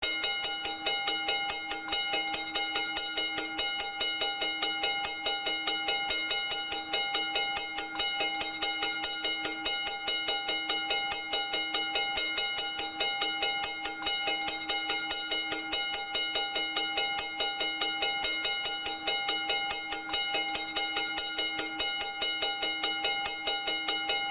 Geluidsbestanden mechanische overwegbellen
Daarbij onder andere deze opname in Gaanderen gemaakt van de lage bel: dit is misschien wel de beste opname die ik tot nu toe gemaakt heb.
De bel in deze opname heeft een iets andere frequentie dan m'n eerder geplaatste versies, en gaat iets sneller.
overwegbel_laag_gaanderen.mp3